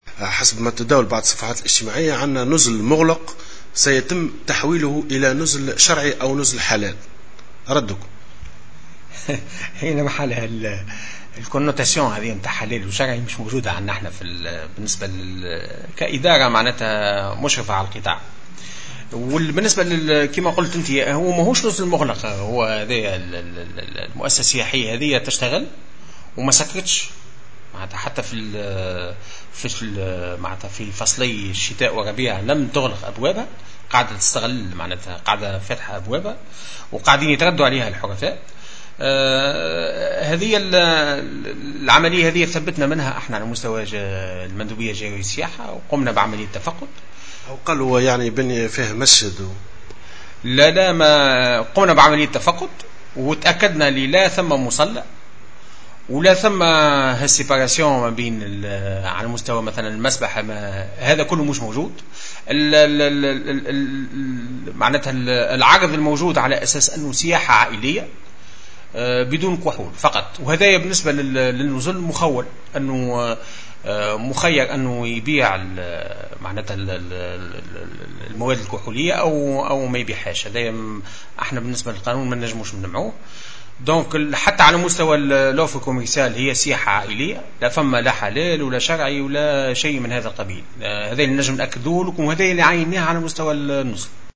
نفى المندوب الجهوي للسياحة بالمهدية، محمد المهدي الحلوي في تصريح لمراسل "الجوهرة أف أم" الأخبار التي يتم ترويجها بخصوص وجود نزل "حلال" بالجهة.